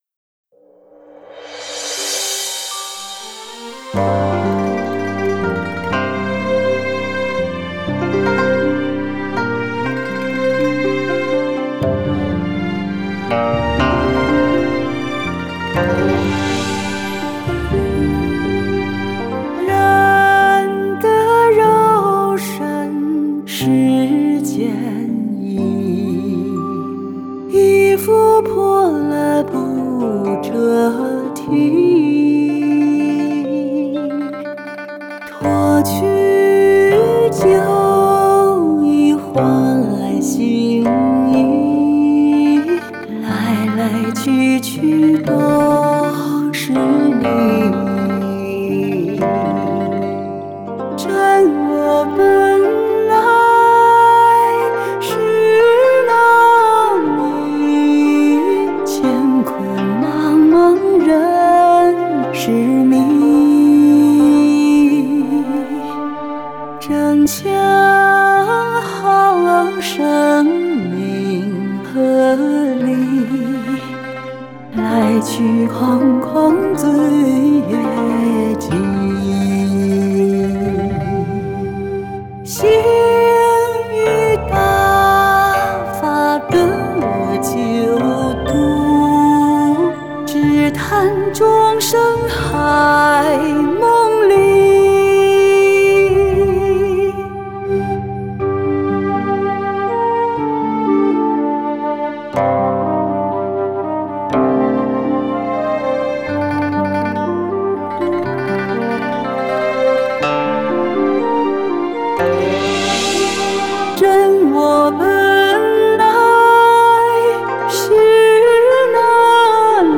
女聲獨唱：塵夢醒 | 法輪大法正見網